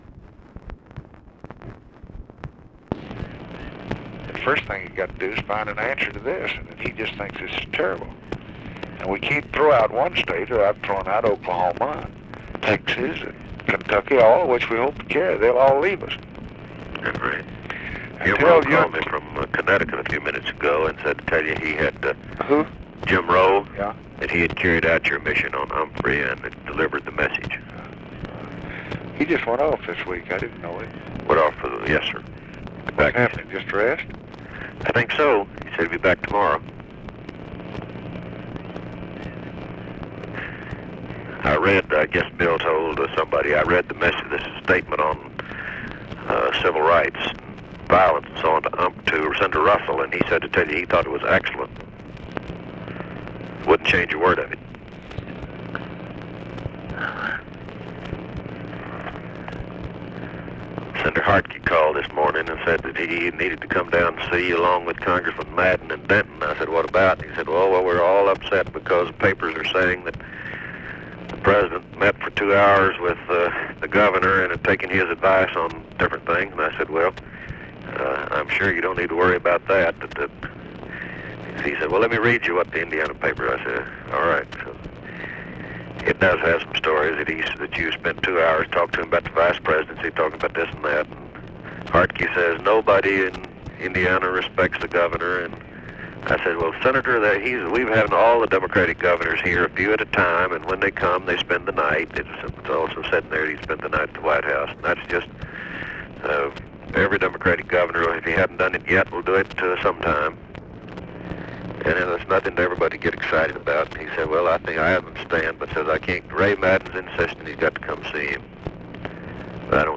Conversation with WALTER JENKINS and BILL MOYERS, August 8, 1964
Secret White House Tapes